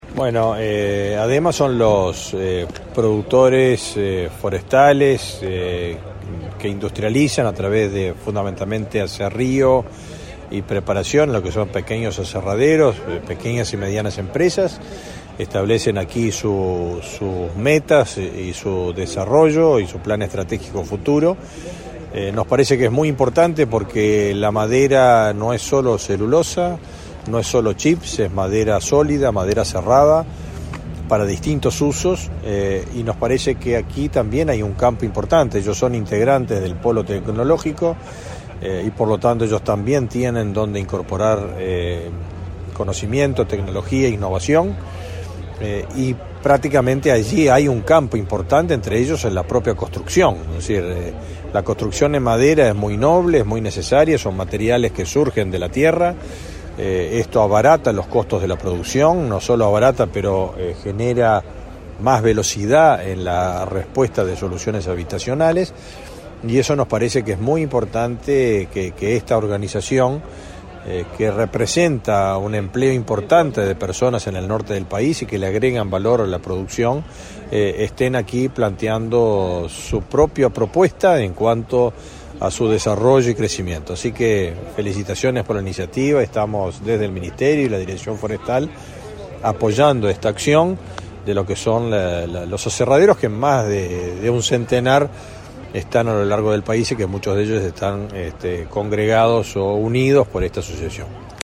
Declaraciones del ministro de Ganadería, Agricultura y Pesca, Fernando Mattos
Previo al evento, el ministro de Ganadería, Agricultura y Pesca, Fernando Mattos, efectuó declaraciones a Comunicación Presidencial.